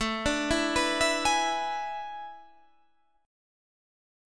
AM7sus2sus4 chord